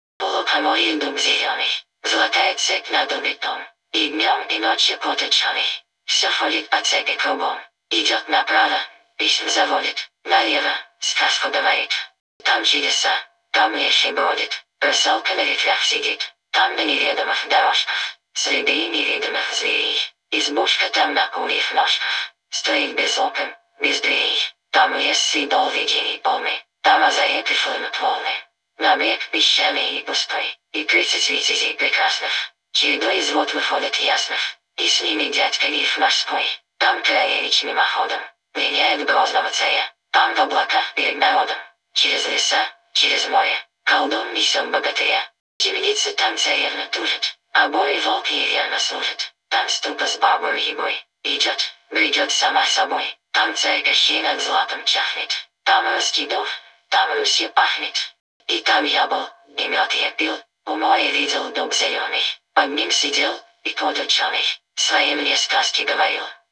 so-vits-svc
Fallout_4 / English /F4_F_DLC01RobotCompanionFemaleDefault_Eng /OLD /G_1000 (Rus).wav